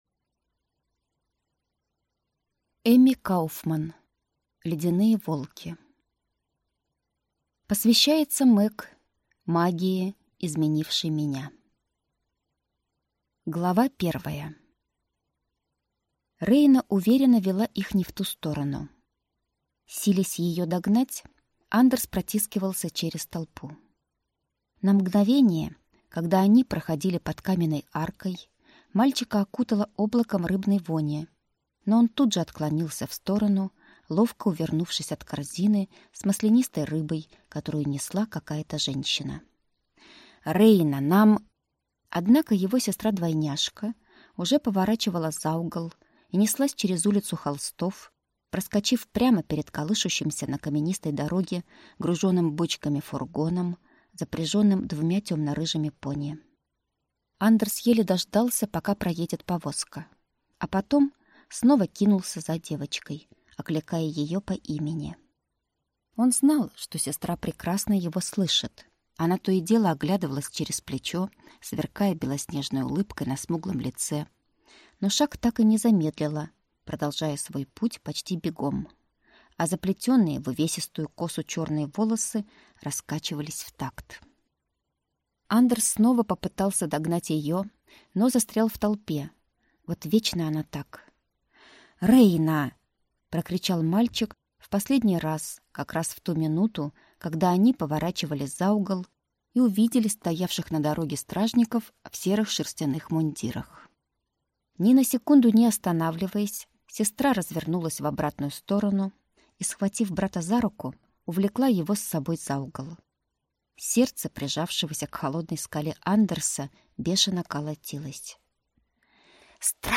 Аудиокнига Ледяные волки | Библиотека аудиокниг